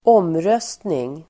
Uttal: [²'åm:rös:tning]